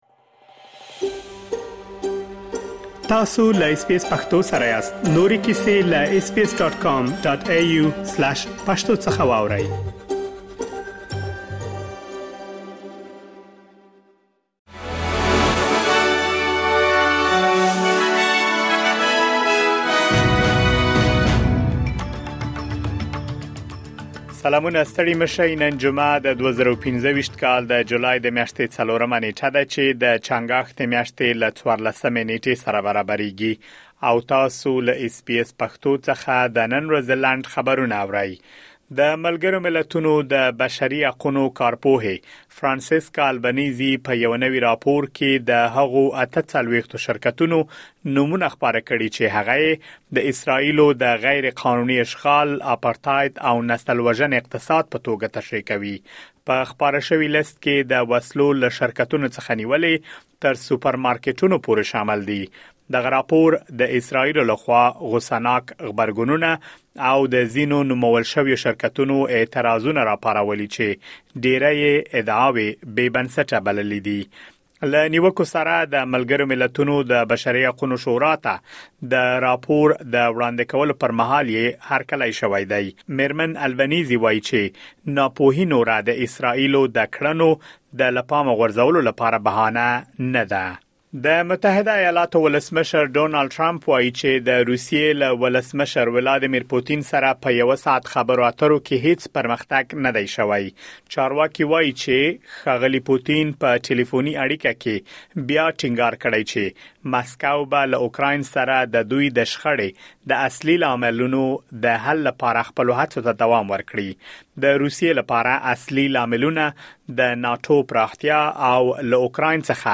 د اس بي اس پښتو د نن ورځې لنډ خبرونه |۴ جولای ۲۰۲۵